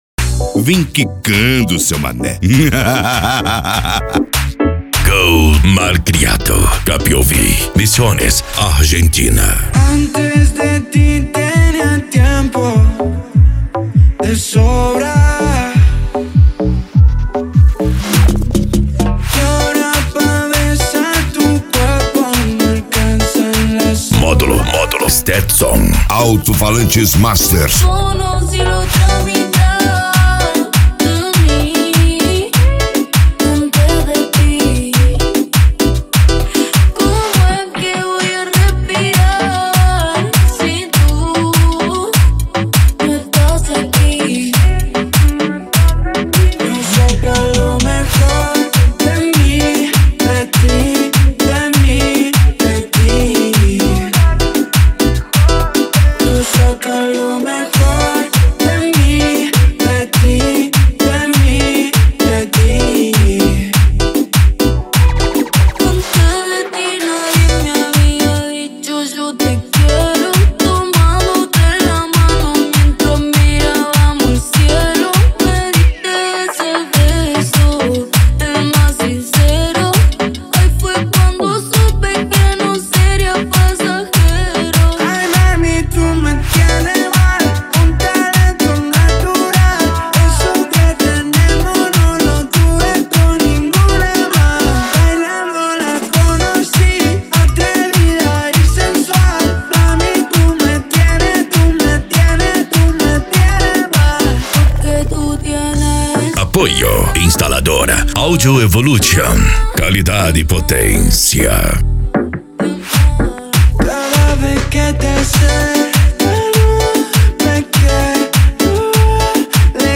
Arrocha
Funk
Mega Funk
PANCADÃO
Racha De Som
Reggaeton
Remix